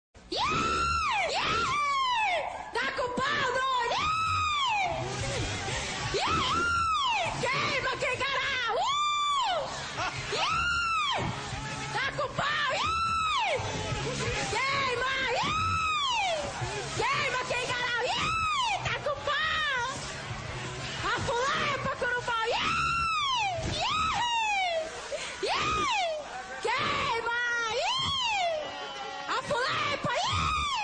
Play Vaia De Cearense!
vaia-de-cearense.mp3